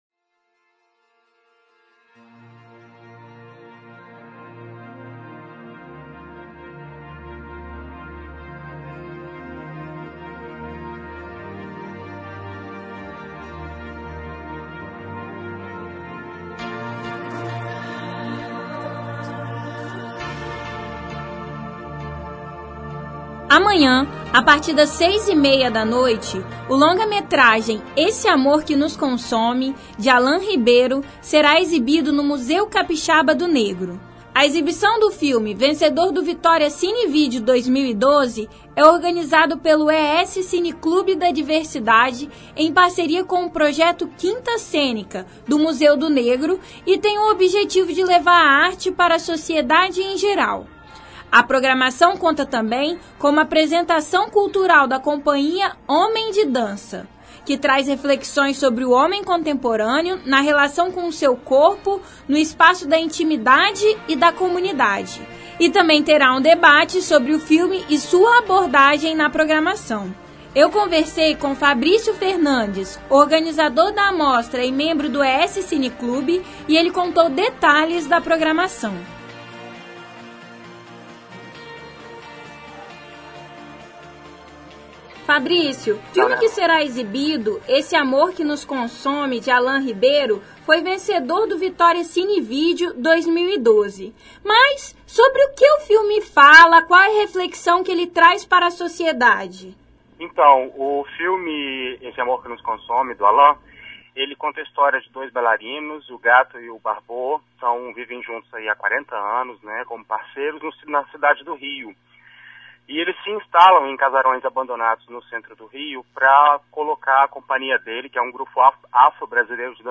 Matéria sobre a exibição do filme "Esse amor que nos consome", de Allan Ribeiro, no Museu Capixaba do Negro.